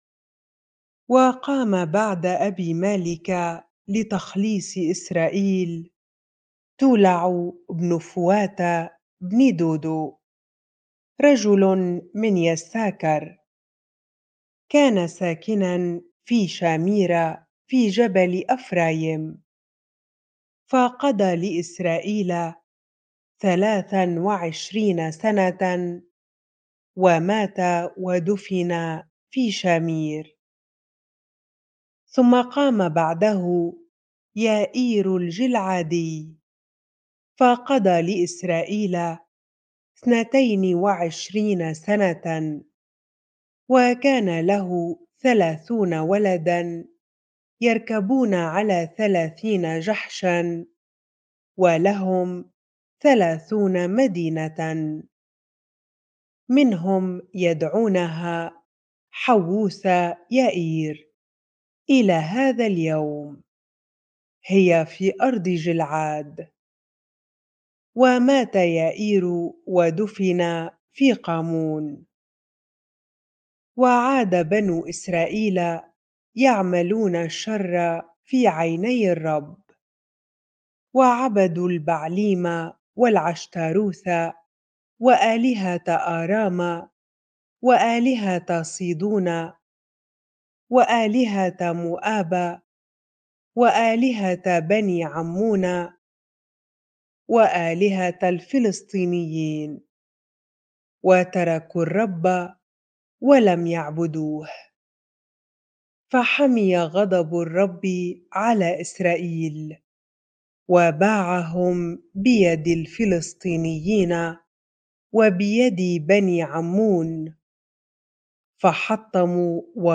bible-reading-Judges 10 ar